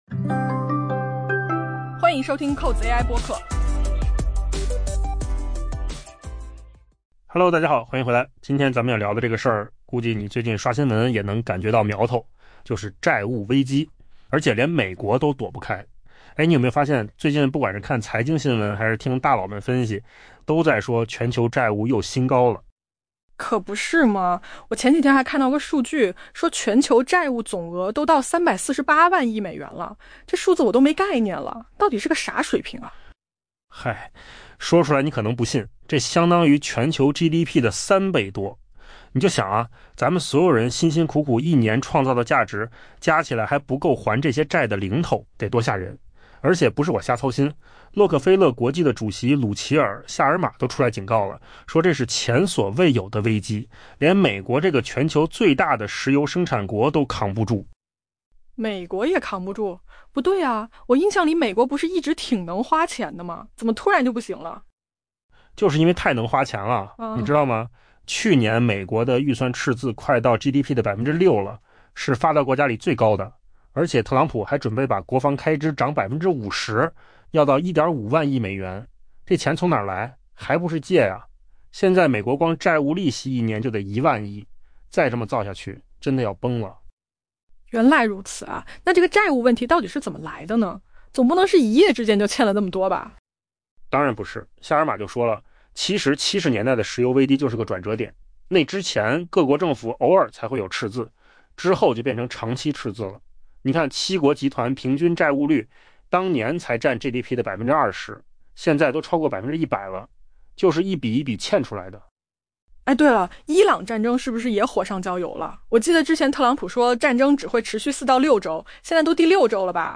AI播客：换个方式听新闻 下载mp3 音频由扣子空间生成 洛克菲勒资本管理公司旗下全球投资策略部门——洛克菲勒国际主席鲁奇尔・夏尔马（Ruchir Sharma）表示， 全球正陷入一场前所未有的危机，而当前全球债务规模已达历史峰值，这使得即便身为全球最大石油生产国的美国，也显得格外脆弱。